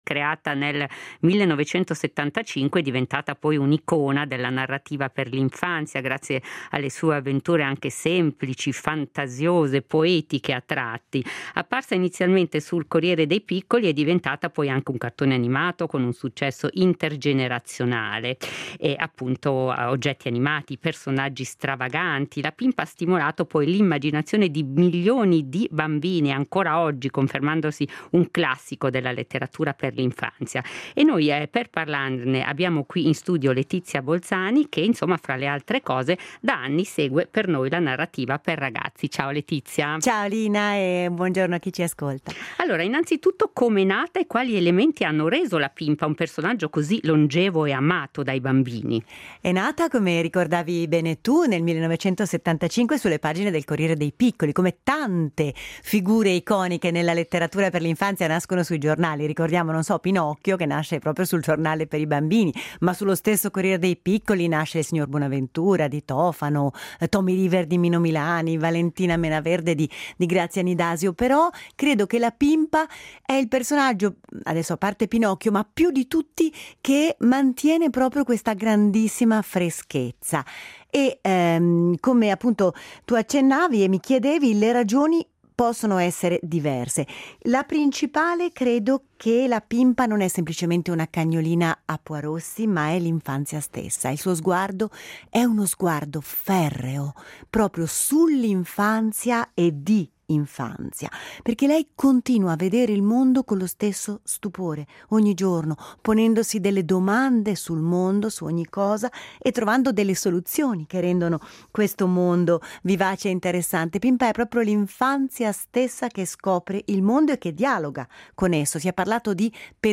L’episodio di Alphaville andata in onda alla radio della RSI: